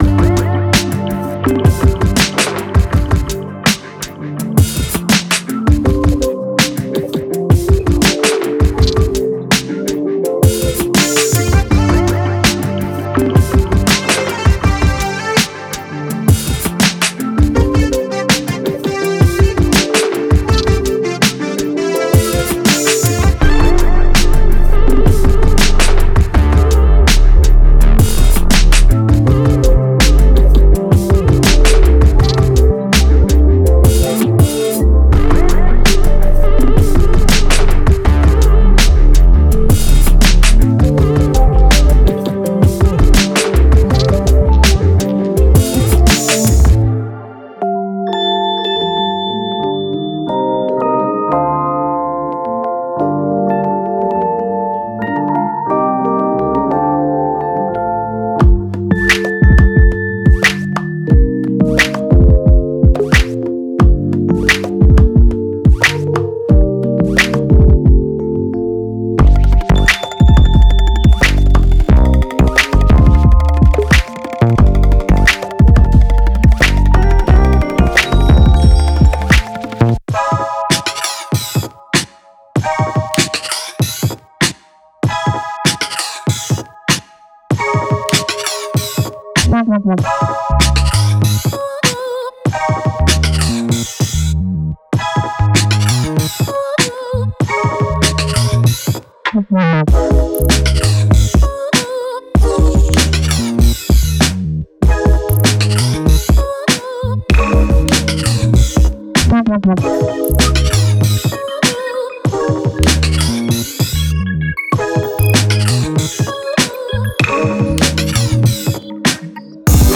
Genre:Soul and RnB
モダンなR&Bにヒップホップやポップスの要素を取り入れたこのサンプルパックは、多様性と成熟度の両方を醸し出している。
ヴィンテージの美学と現代的なセンスが融合したユニークな仕上がりです。
デモサウンドはコチラ↓